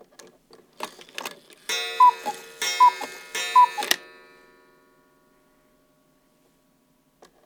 cuckoo_strike3.L.wav